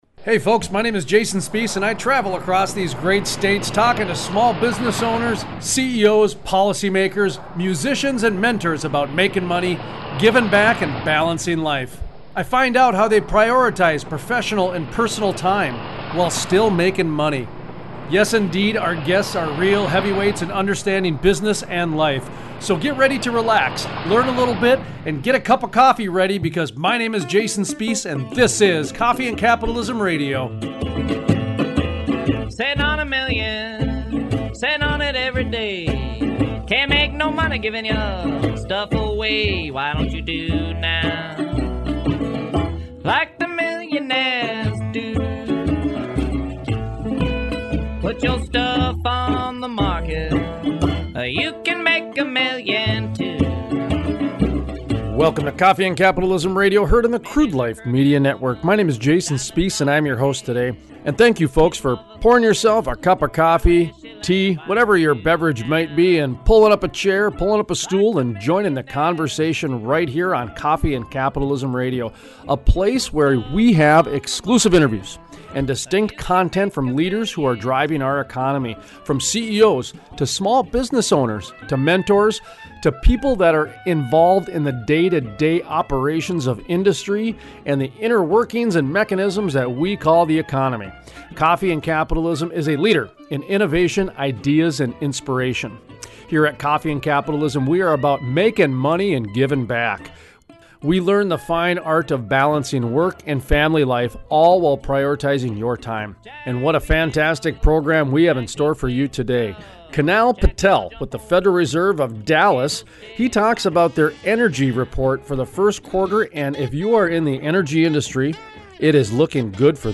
Energy Economy Interview
Small Business USA Interview
Singer-Songwriter Bumper Music Performances